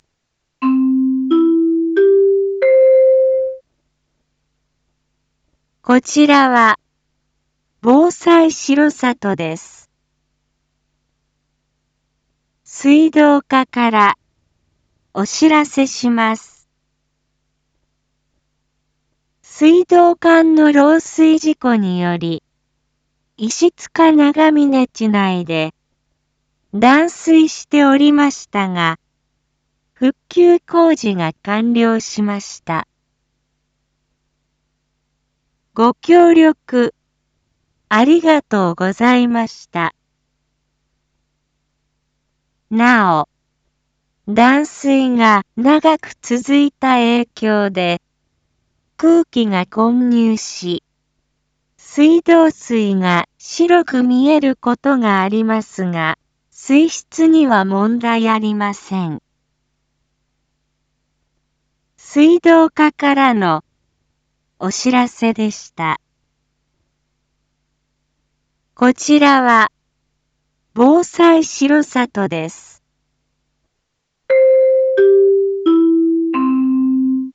Back Home 一般放送情報 音声放送 再生 一般放送情報 登録日時：2023-10-26 17:11:18 タイトル：R5.10.26石塚地内断水復旧 インフォメーション：こちらは、防災しろさとです。